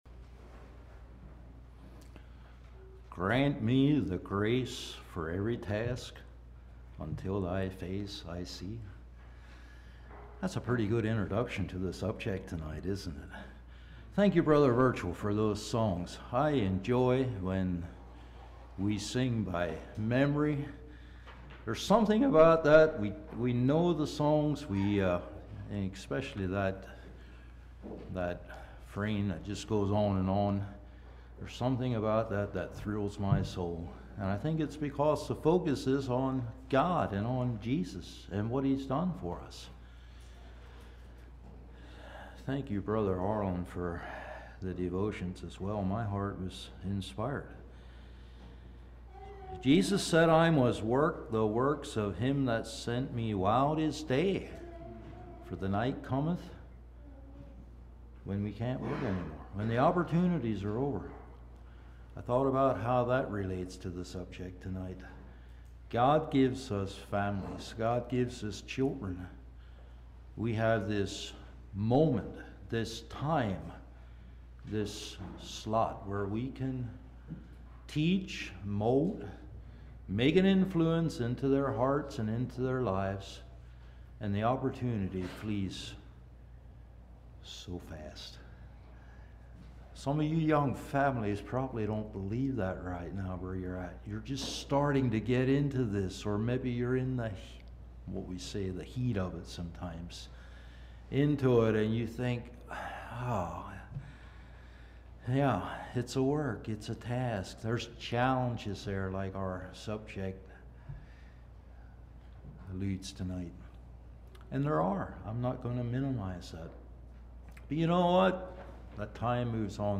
Play Now Download to Device Embracing the Challenge of Parenting Congregation: Providence Speaker